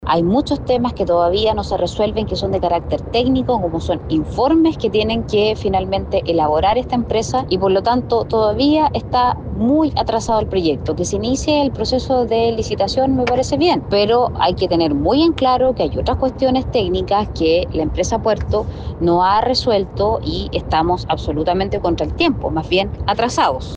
Por otro lado, la parlamentaria de Renovación Nacional, Camila Flores, declaró que le parece bien el inicio de la licitación, pero que se encuentra muy atrasado el proyecto.